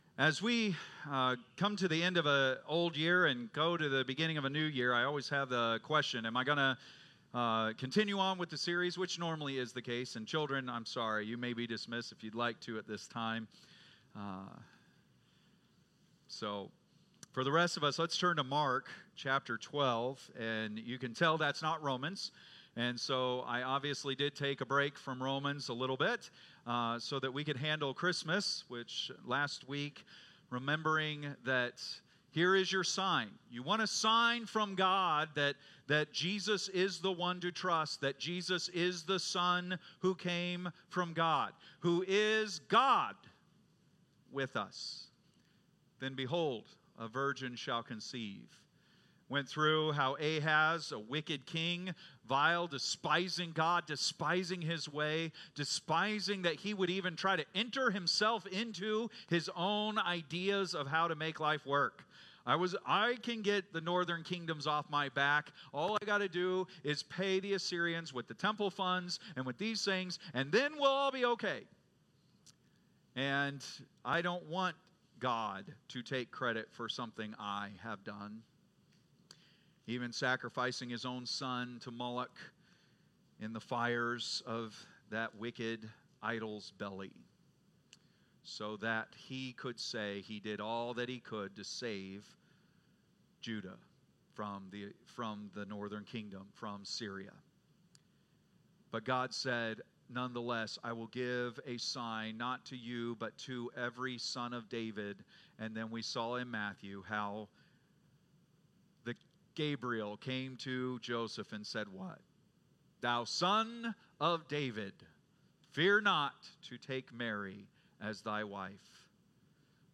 Date: December 29, 2024 (Sunday Morning)